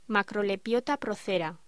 Locución: Macrolepiota procera